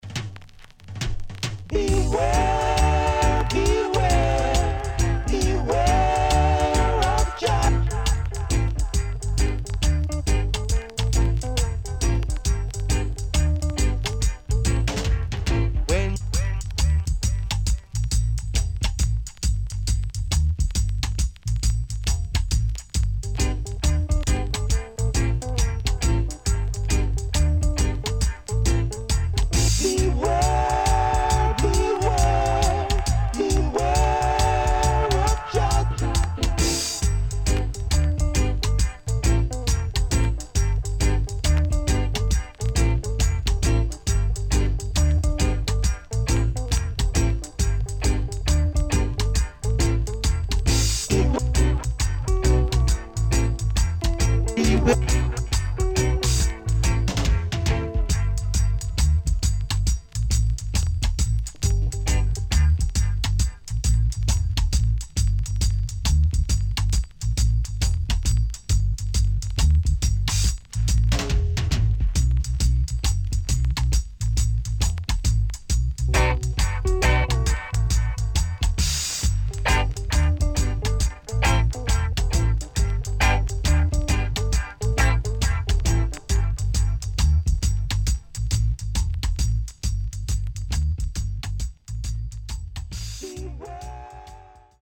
Rare.Uptempoなリズムに優しい歌声とChorusが最高なRoots Tune.Recommend!!
SIDE A:所々チリノイズがあり、少しプチノイズ入ります。